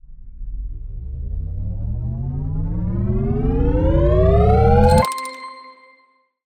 Charging_5S.wav